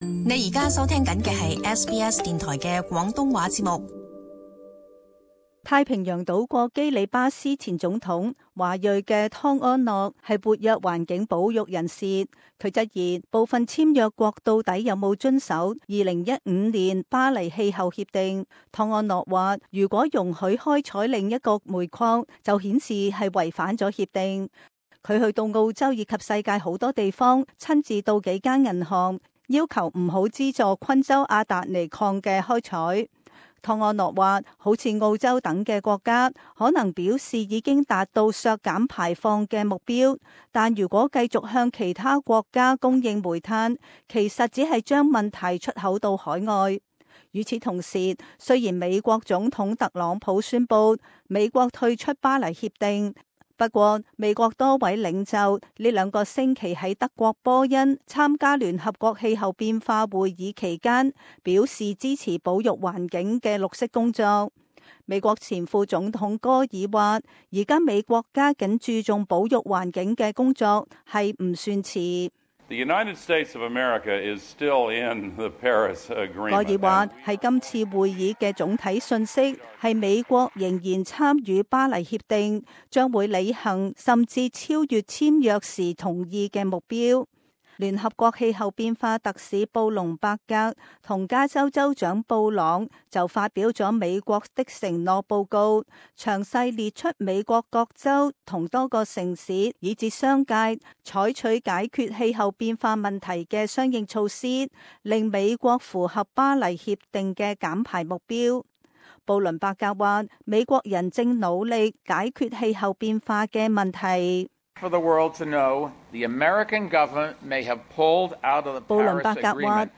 【時事報導】澳州在聯合國氣候峰會立場如何？